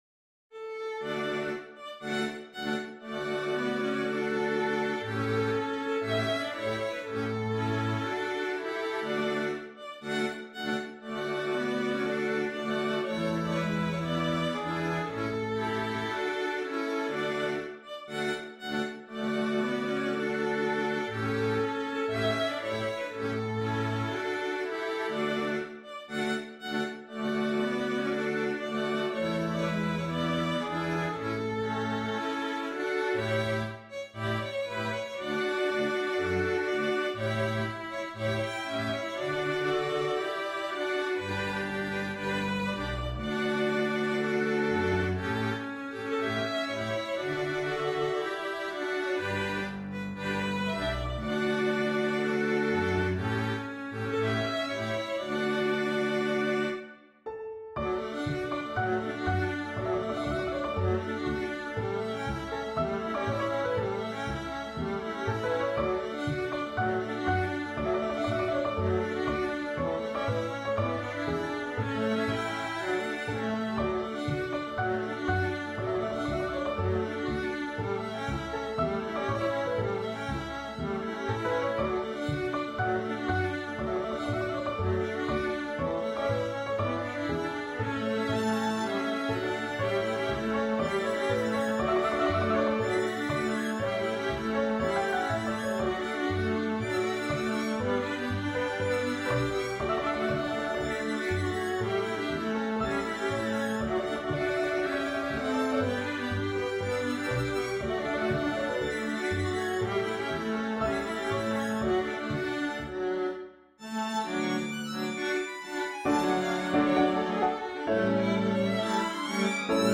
Music for Strings